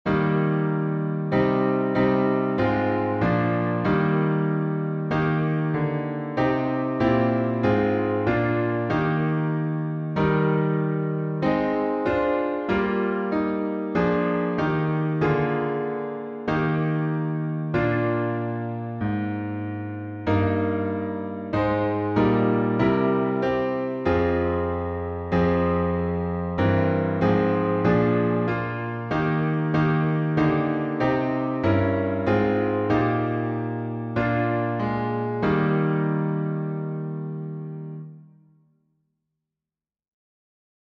Key signature: E flat major (3 flats) Time signature: 4/4 Meter: Irregular